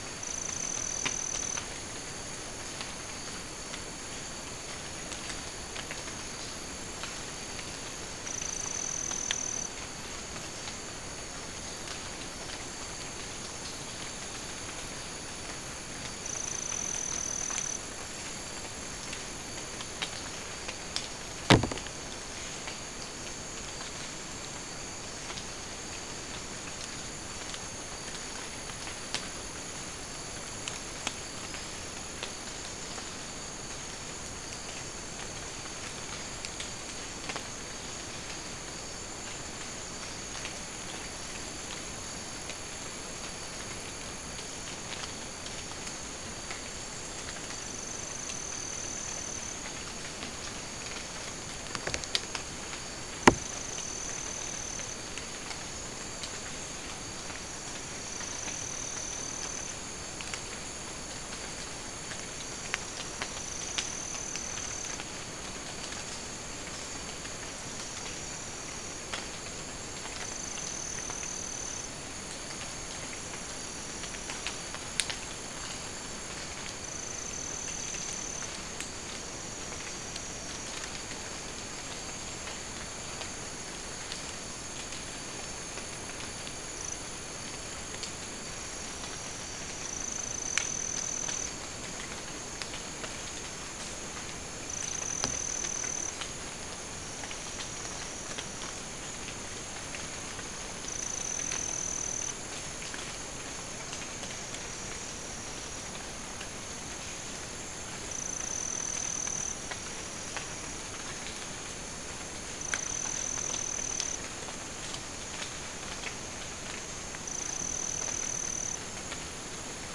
Soundscape Recording Location: South America: Guyana: Rock Landing: 1
Recorder: SM3